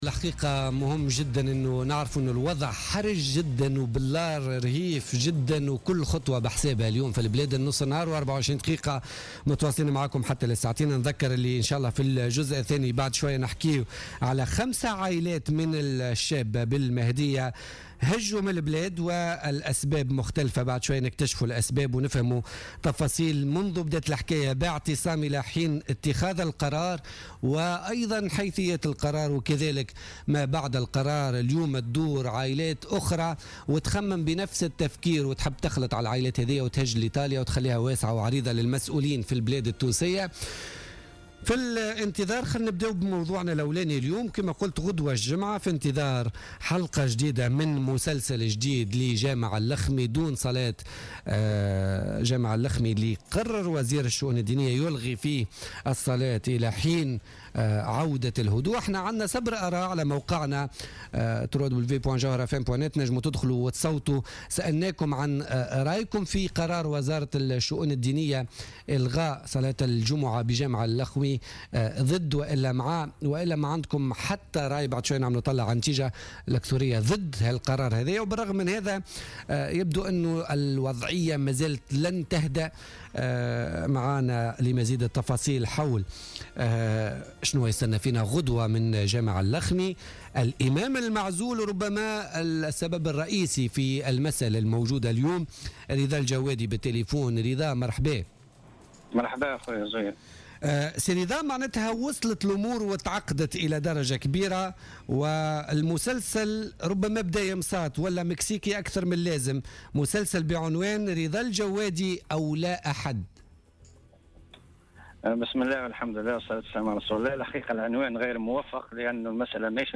في مداخلة له في برنامج بوليتيكا